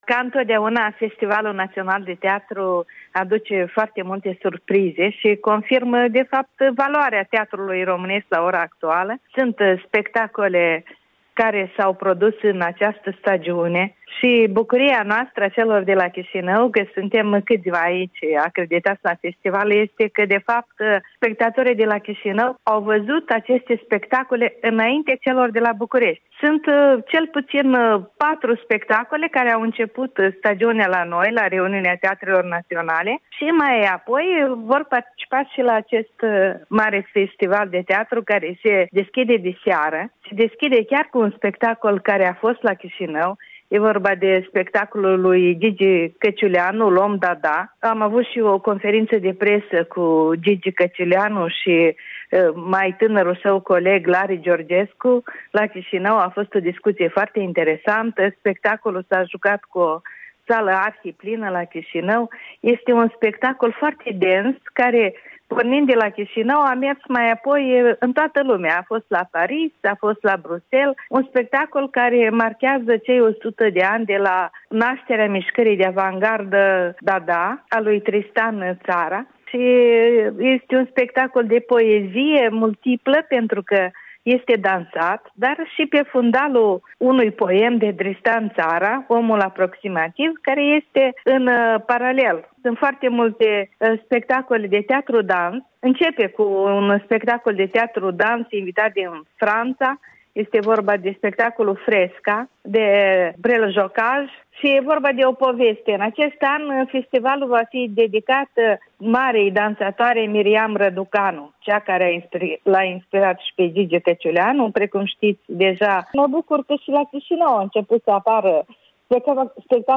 Un interviu în prima zi a FNT, la București.